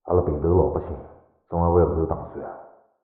三楼/囚室/肉铺配音偷听效果处理